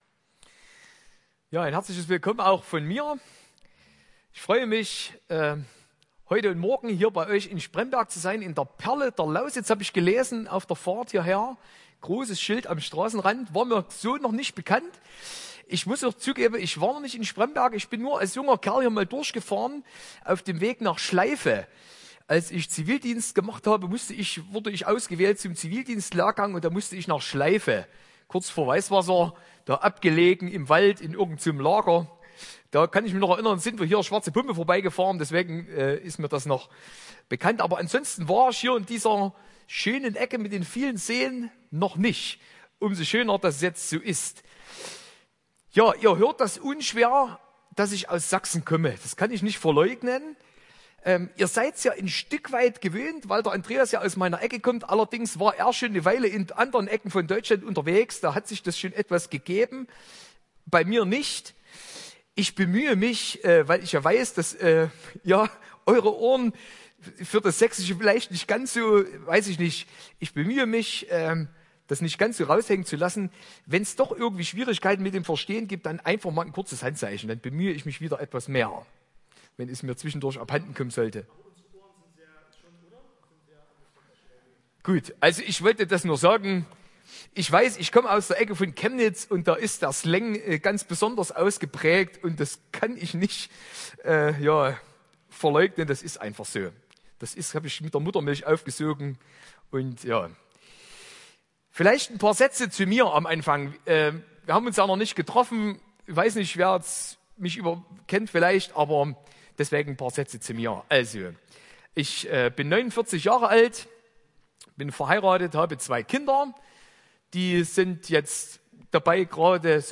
Wer sich das Seminar anhören möchte, findet hier die Audioaufnahmen dazu.
Vortrag 1 (Freitagabend)